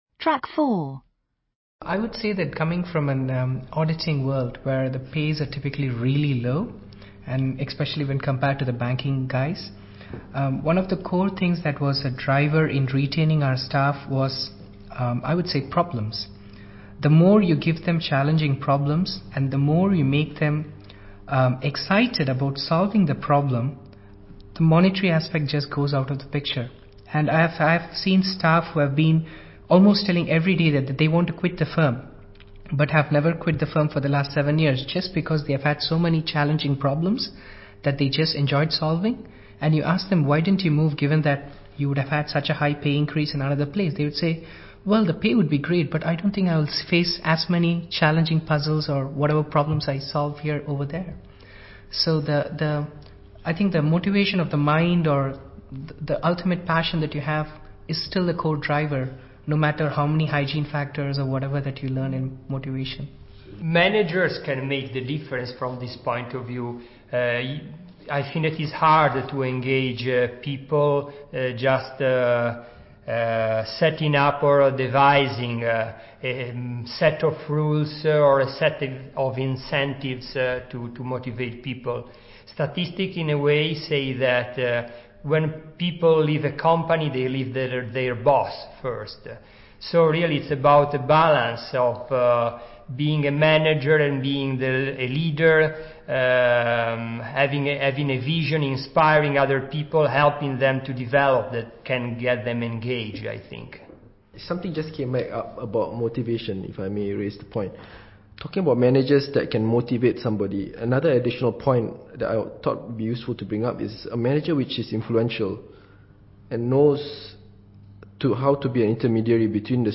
Listen to three MBA students at the Judge Business School talking about motivation, and answer these questions.